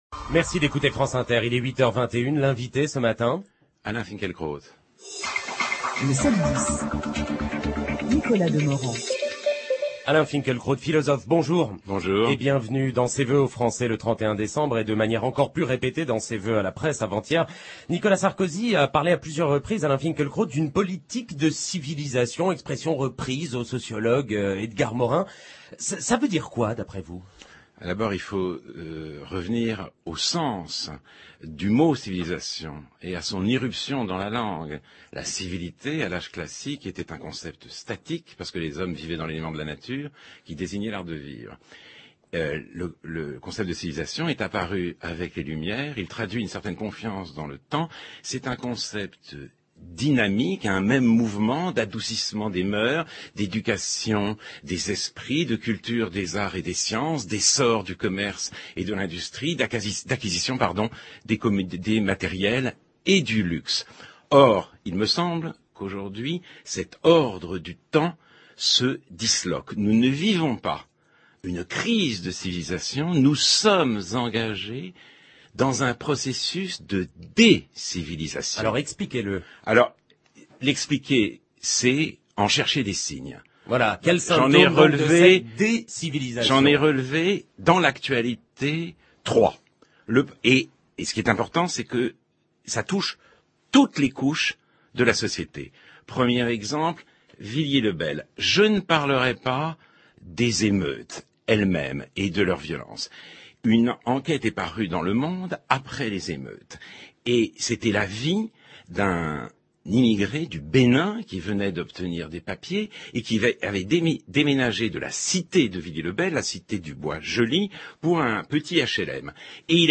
Sur France Inter, le jeudi 10 janvier , Alain Finkielkraut est « l’invité d’Inter » de Nicolas Demorand. Il explique que nous sommes engagés dans un processus de « dé-civilisation » et en prend comme exemple, pour le dénoncer, une abjection: Chez Ardisson, au cours de l’émission de rentrée de « Salut les Terriens! » sur « Canal + » on doit choisir le mort le moins important de l’année.